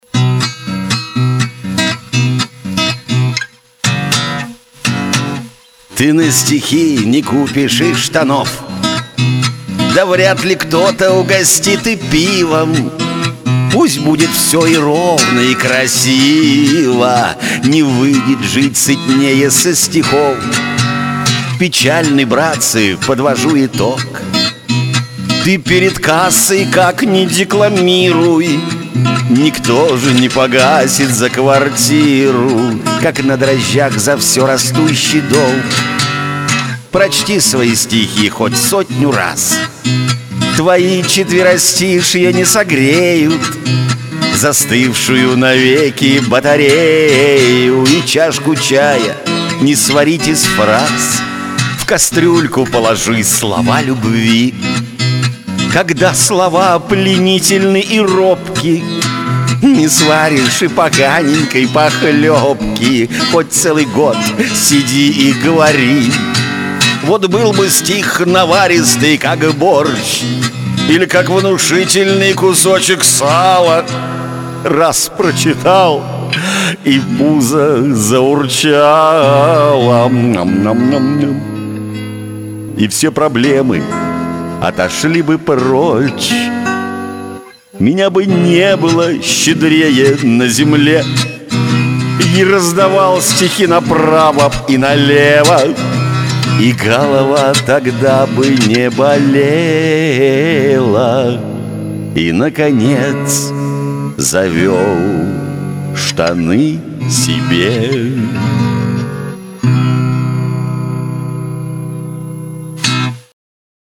Правда, "на коленке" записано, делайте скидку))))
Одна - шуточная - "Про стихи_алчное" получилась, другая - лирическая "Я так скучаю".
Изумительный голос и блестящая игра на гитаре!!!